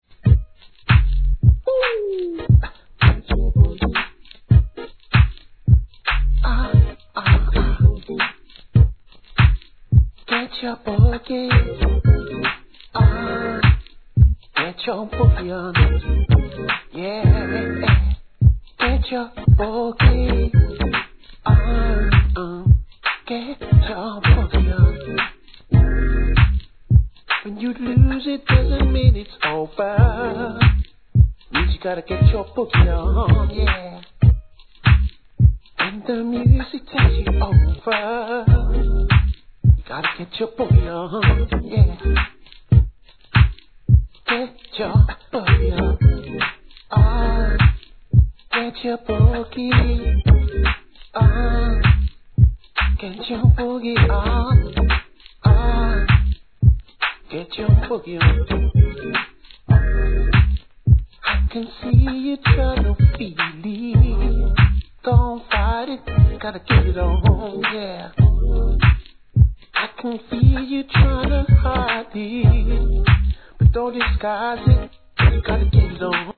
HIP HOP/R&B
スモーキーでソウルフルな男性ヴォーカルにスペイシーなシンセが心地良い!